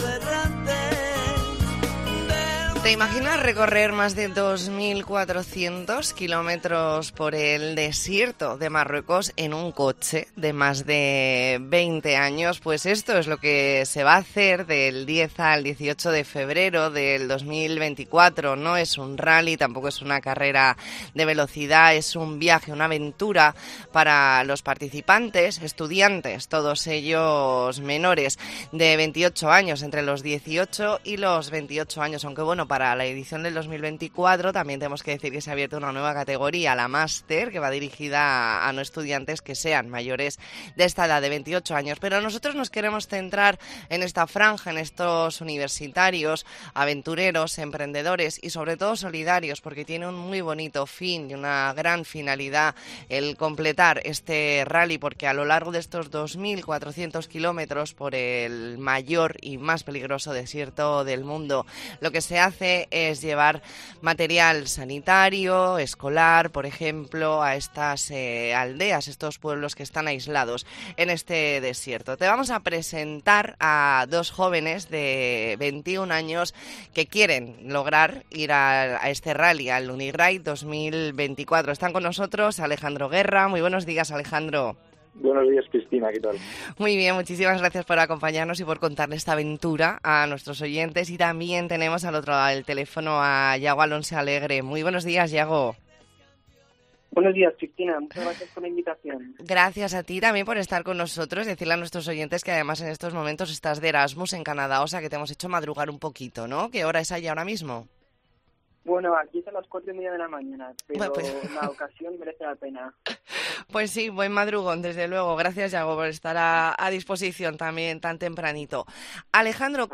Entrevista en La Mañana en COPE Más Mallorca, jueves 26 de octubre de 2023.